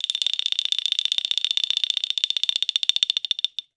07 PICCOLO R.wav